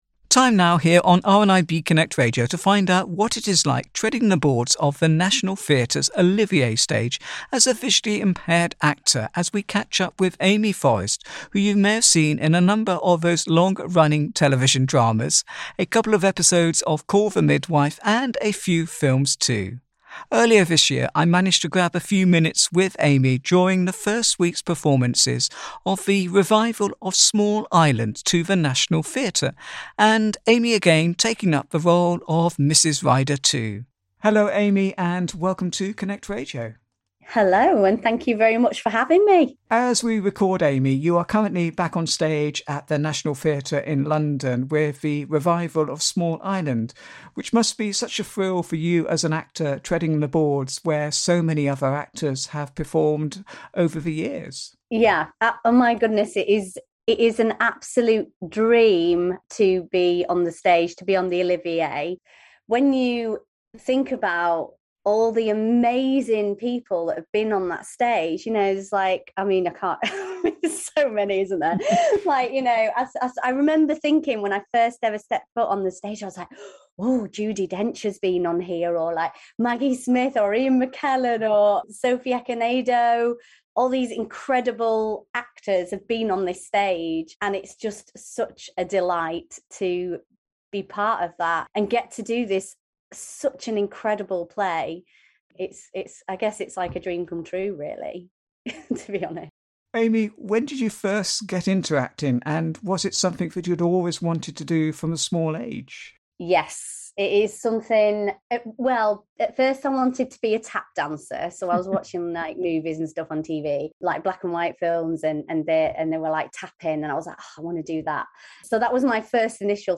Profile Interview